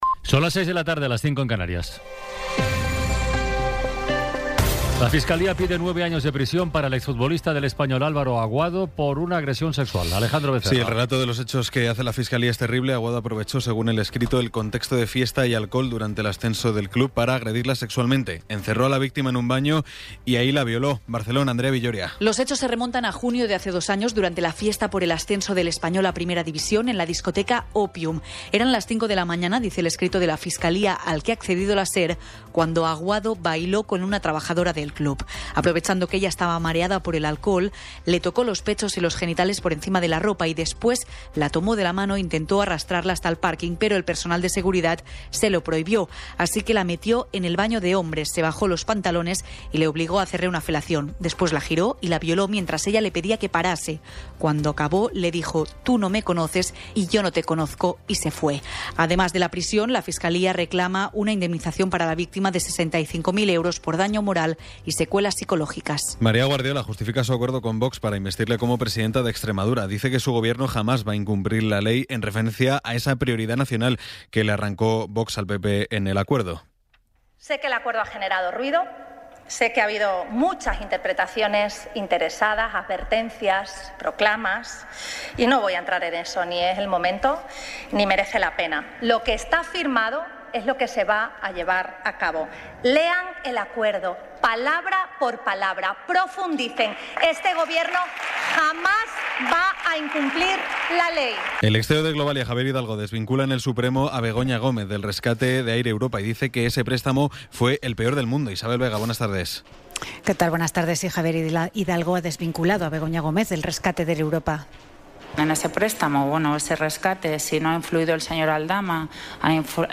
Resumen informativo con las noticias más destacadas del 21 de abril de 2026 a las seis de la tarde.